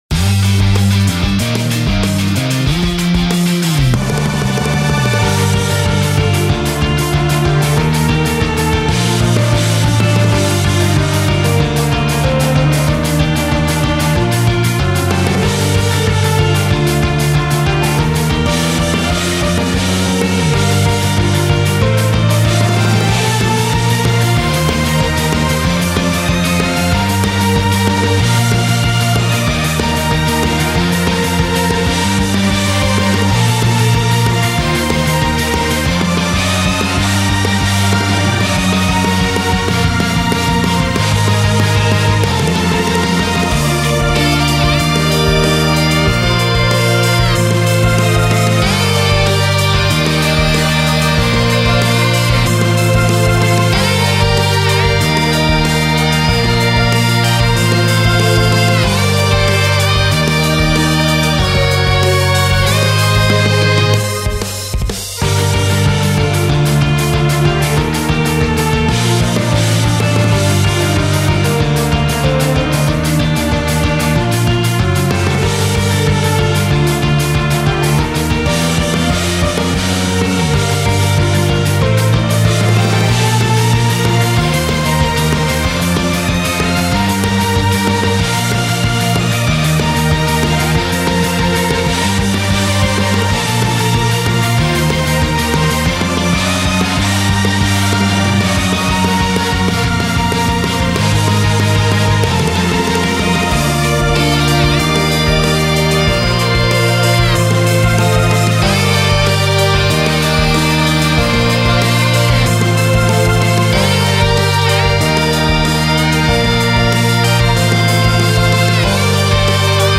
ジャンルロック、ポップ
BPM１８８
使用楽器ヴァイオリン、エレキギター
解説ポップロック系の戦闘曲フリーBGMです。
ゴシック(Gothic)